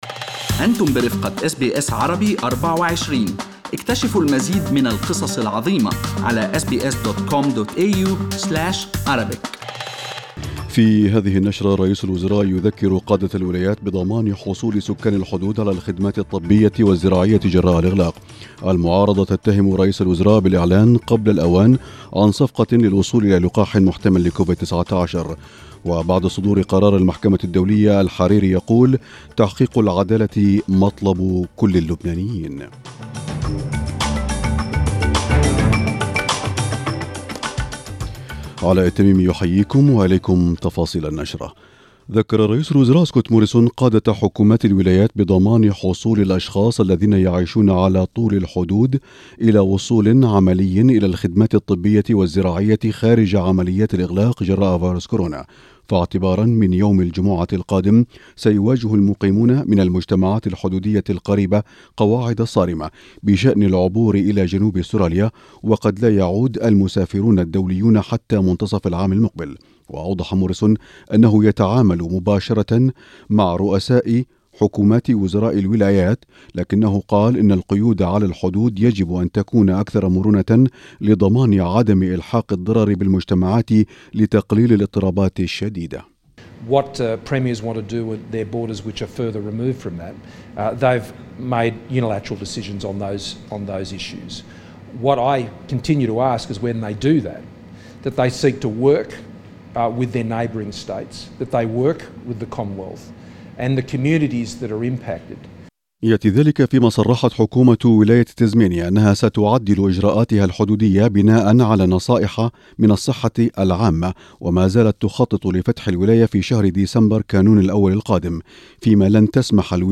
نشرة أخبار المساء 19/8/2020